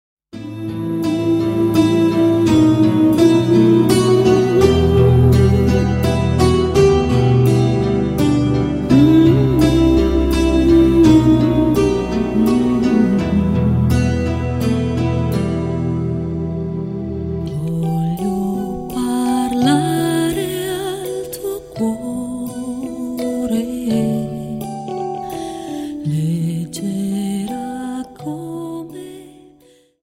Dance: Slow Waltz 28